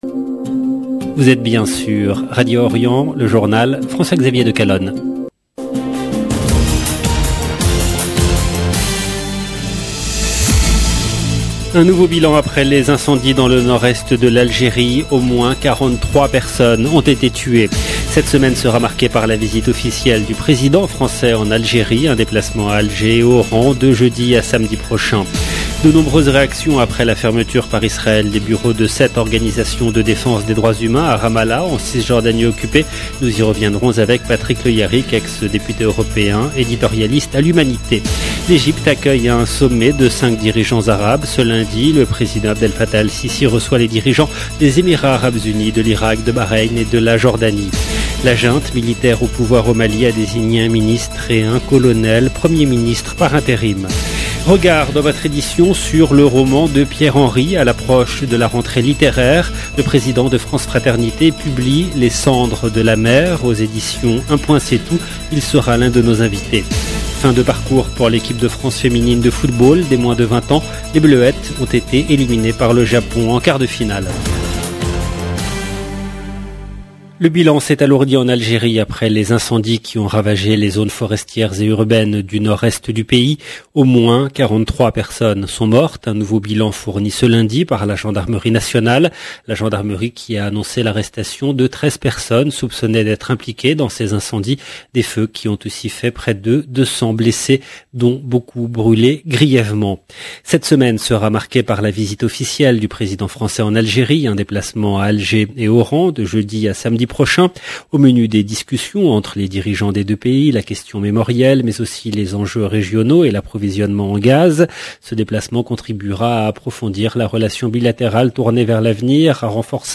EDITION DU JOURNAL DU SOIR EN LANGUE FRANCAISE DU 22/8/2022